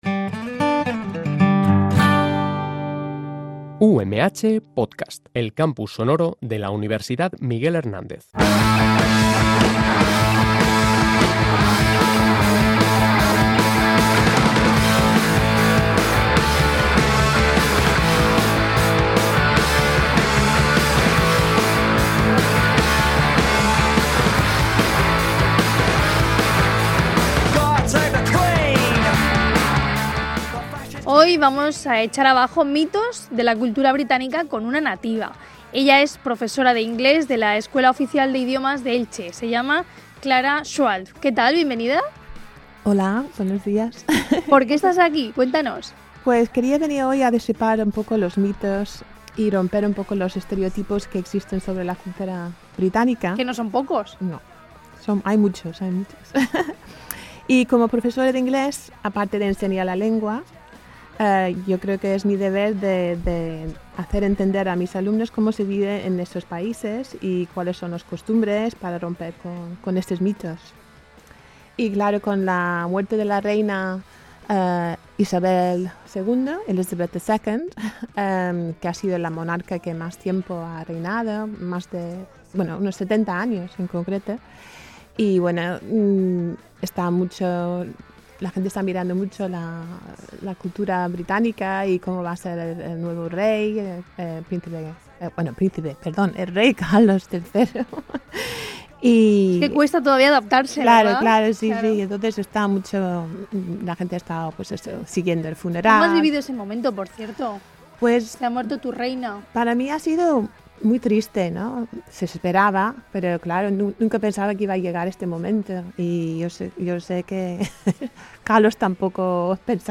Estos son solo algunos de los mitos sobre la cultura británica que hemos abordado hoy con una inglesa, afincada en España, que defiende que la cerveza en su país no se sirve caliente…¡Aunque sí más caliente que aquí!